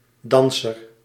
Ääntäminen
IPA : /ˈdɑːns.ə(ɹ)/ IPA : /ˈdæns.ə(ɹ)/